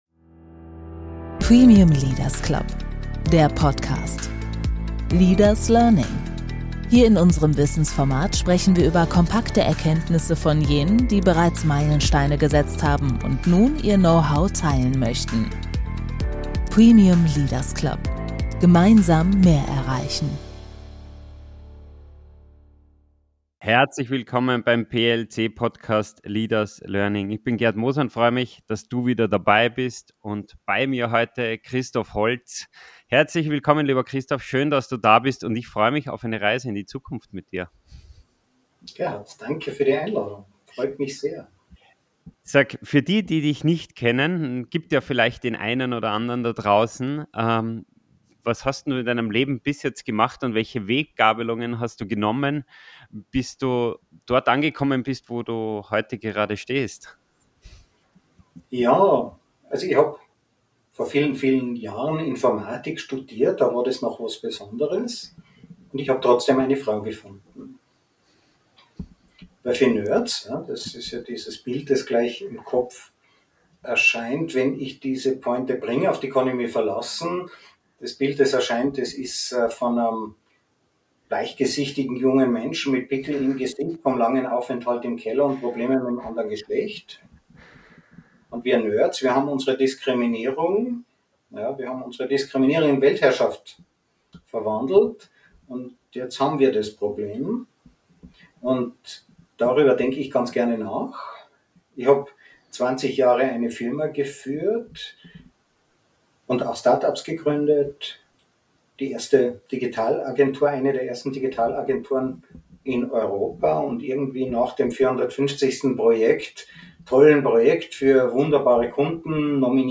Ein Gespräch über den radikalen Wandel unserer Arbeitswelt, die Verantwortung der Tech-Branche – und darüber, warum Zukunft nicht vorhergesagt, sondern gemeinsam verhandelt wird.